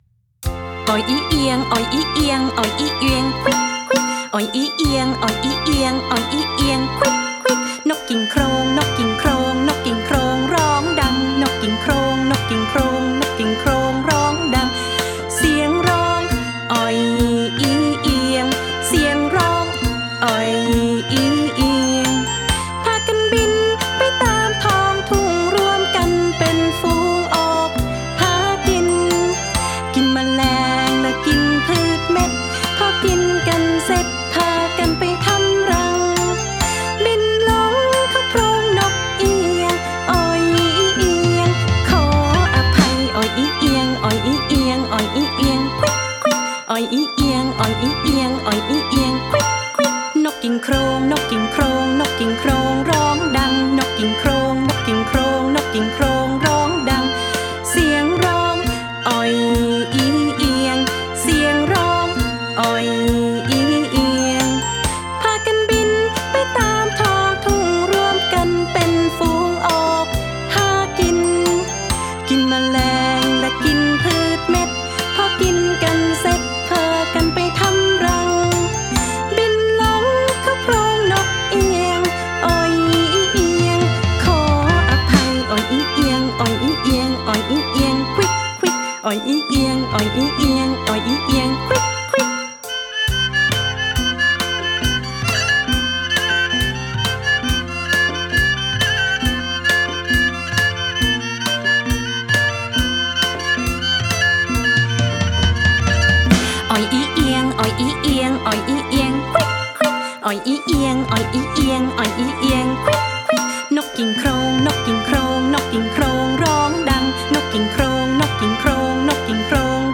ไฟล์เพลงพร้อมเสียงร้อง